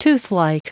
Prononciation du mot : toothlike